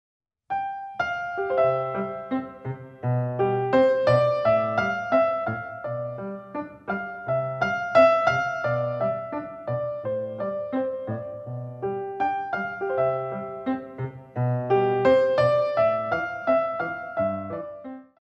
Reverence